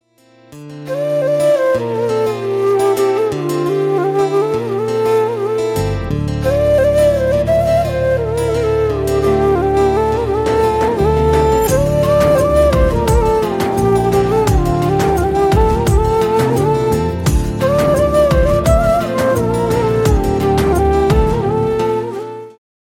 Mp3 Ringtones